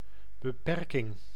Ääntäminen
IPA: [bə.pɛɾ.kiŋ]